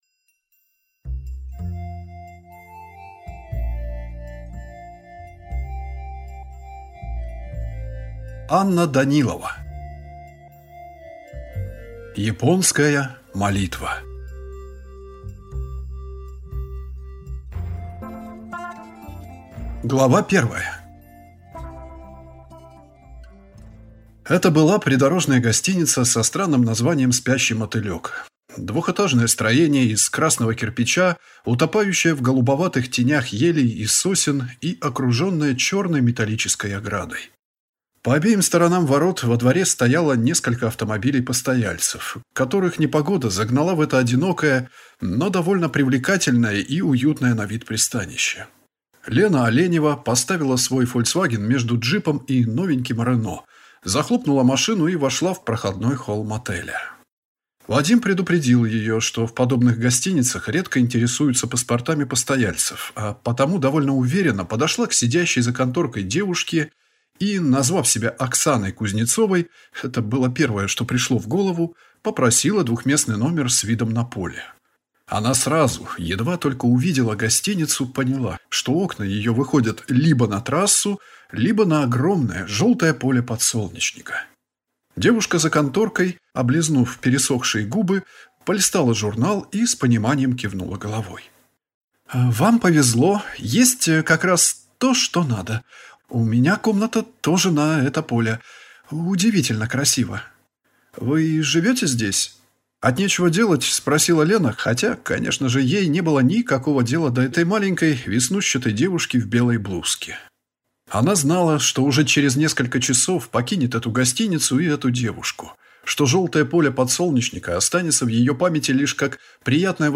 Аудиокнига Японская молитва | Библиотека аудиокниг